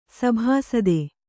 ♪ sabhāsade